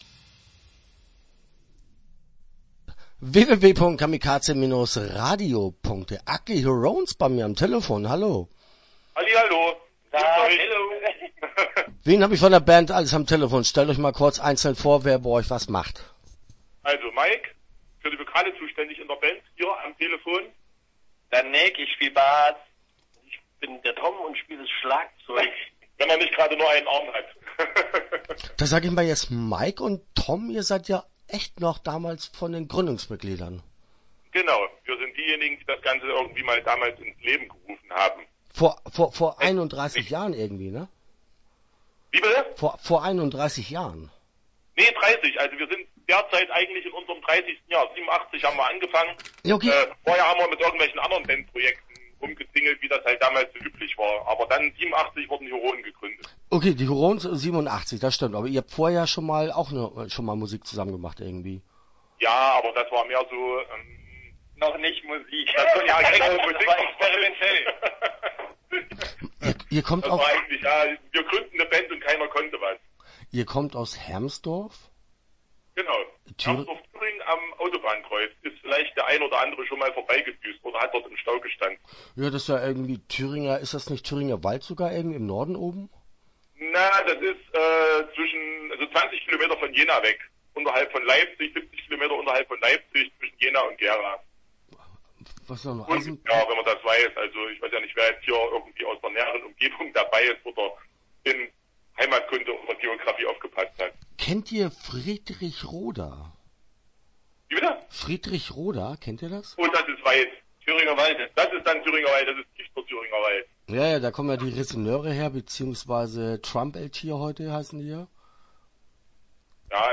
Ugly Hurons - Interview Teil 1 (10:55)